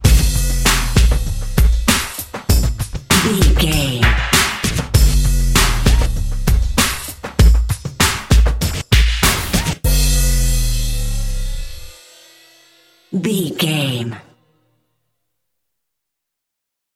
Ionian/Major
drum machine
synthesiser
hip hop
Funk
neo soul
acid jazz
energetic
bouncy
funky